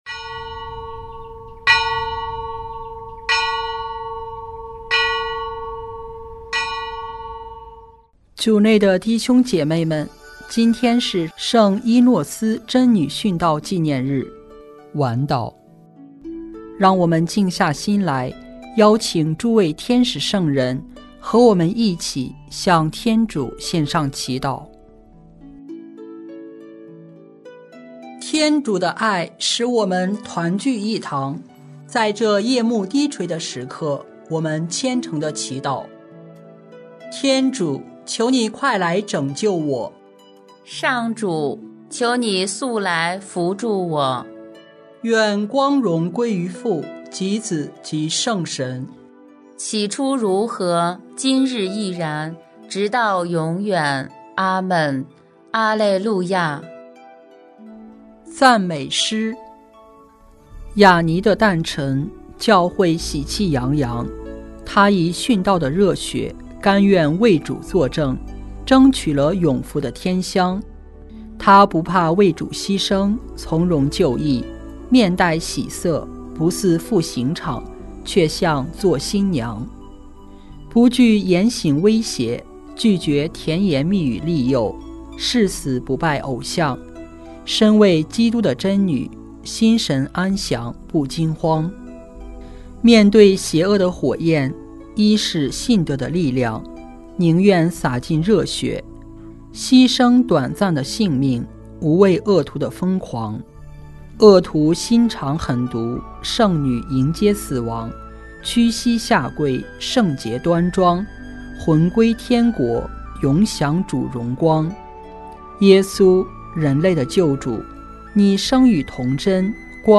圣咏吟唱 圣咏 114 谢恩 “我们必须历尽艰苦，才能进入天主的国。”